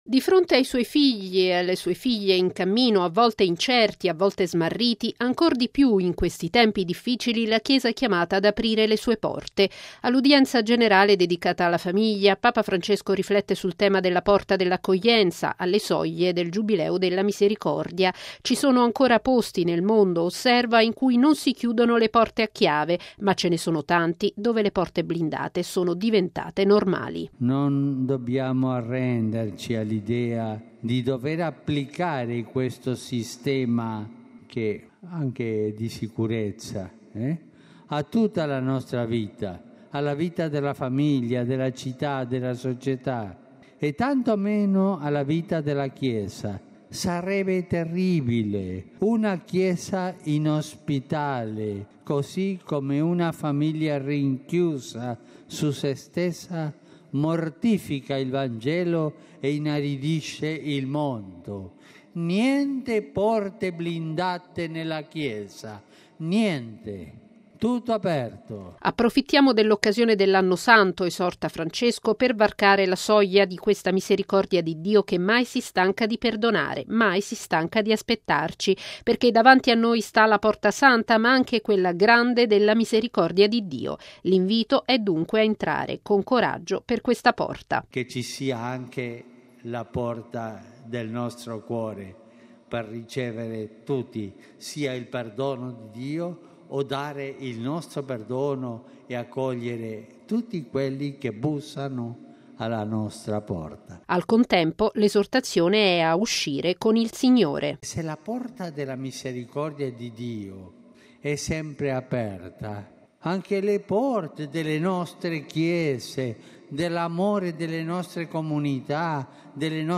Questo l’invito di Papa Francesco all’udienza generale in Piazza San Pietro, durante la quale ha esortato i fedeli a varcare quella porta, alla vigilia del Giubileo, secondo gli incoraggiamenti del Sinodo dei Vescovi appena celebrato. Il servizio